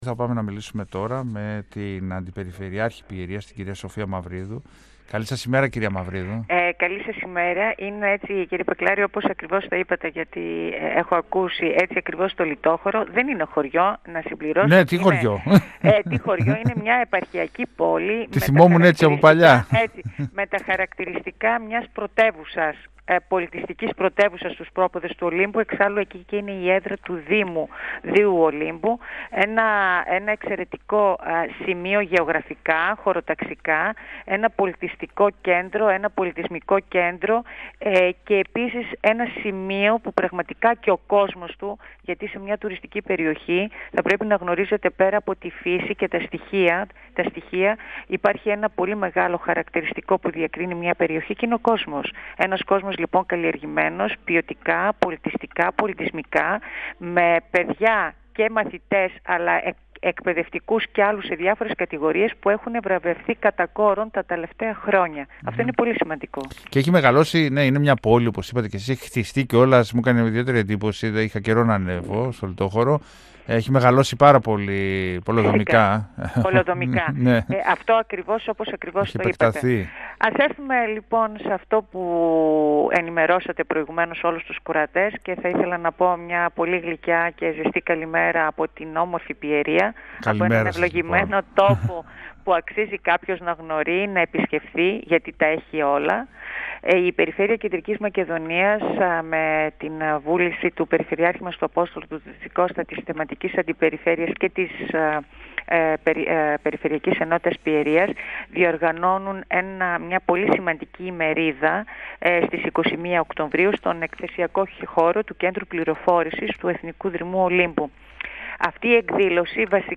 Για την εκδήλωση μίλησε στον 102FM του Ραδιοφωνικού Σταθμού Μακεδονίας της ΕΡΤ3 η αντιπεριφερειάρχης Πιερίας, Σοφία Μαυρίδου. 102FM Συνεντεύξεις ΕΡΤ3